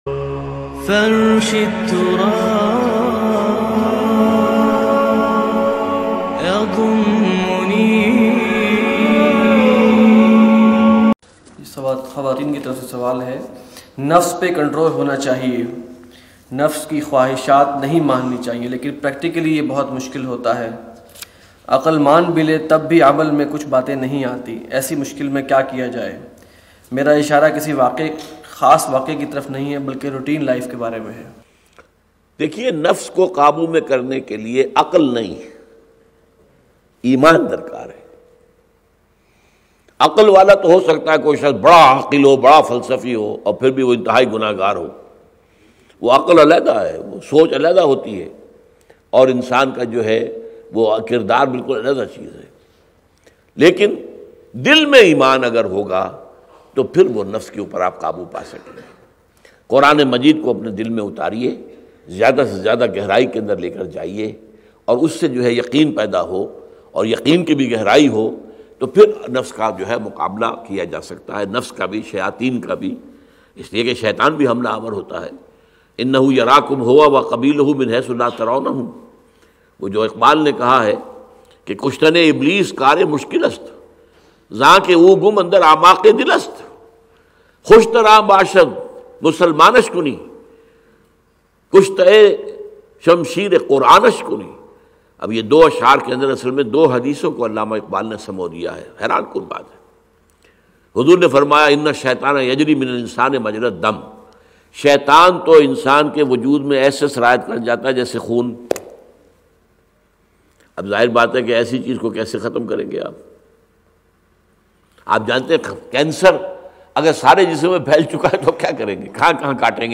Apne Nafs Ko Kaise Control Kiya Jaaye Bayan MP3 Download By Israr Ahmed